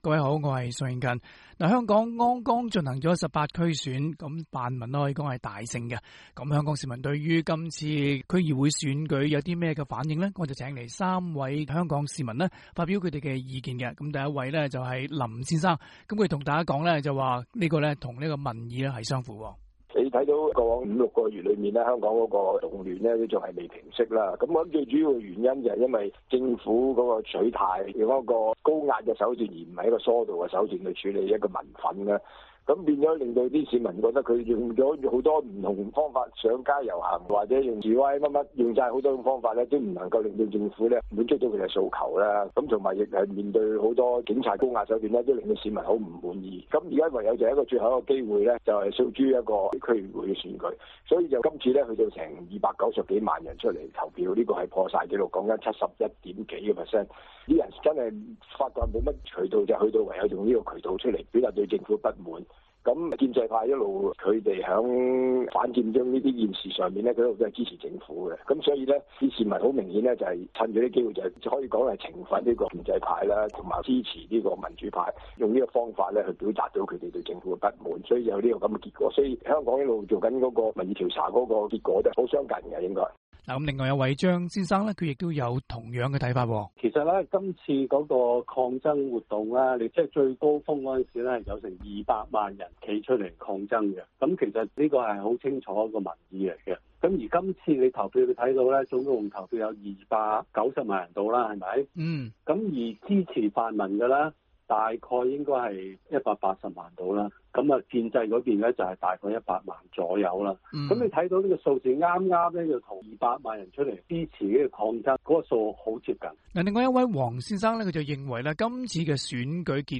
請來三位香港市民表達他們的意見。